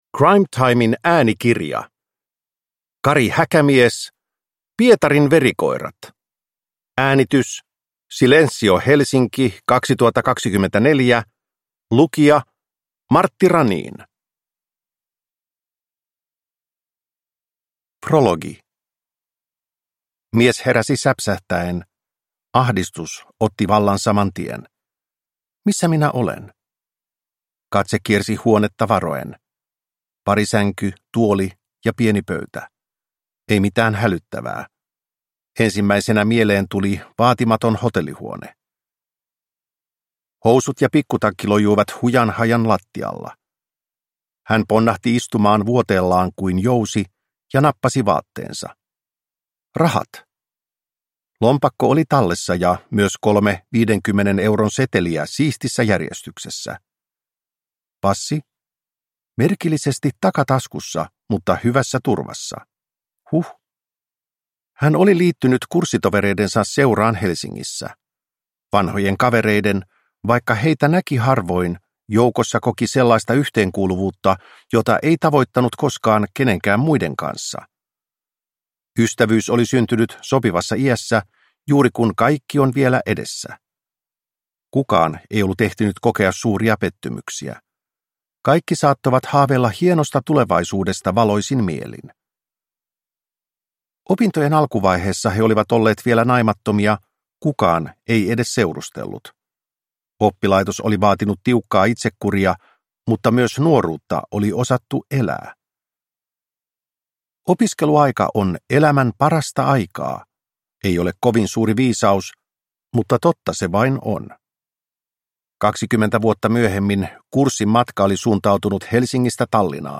Pietarin verikoirat (ljudbok) av Kari Häkämies